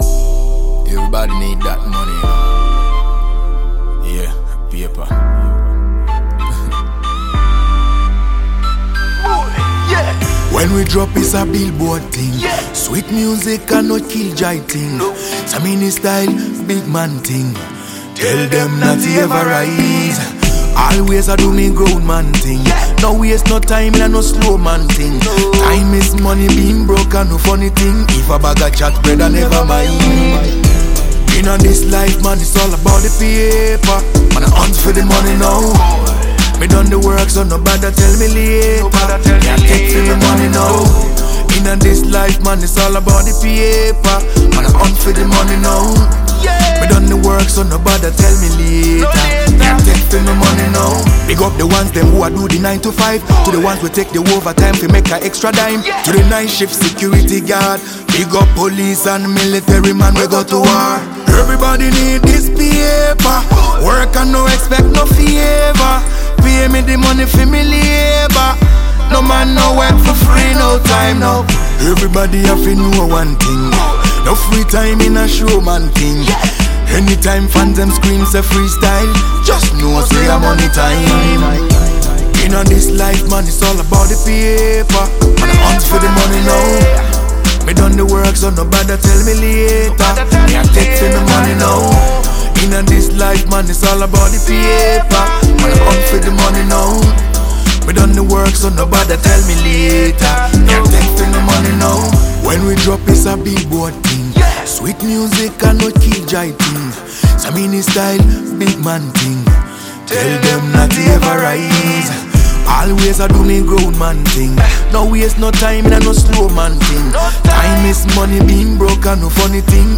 a legendary Ghanaian reggae-dancehall sensation